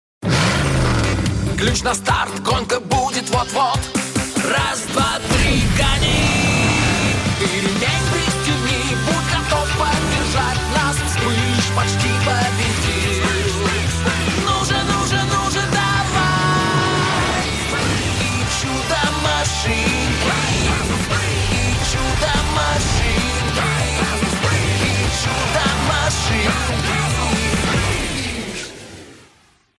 • Качество: Хорошее
• Жанр: Детские песни
🎶 Детские песни / Песни из мультфильмов